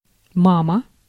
Ääntäminen
IPA : [mɑm]